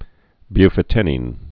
(byfə-tĕnēn, -ĭn) or bu·fo·ten·in (-ĭn)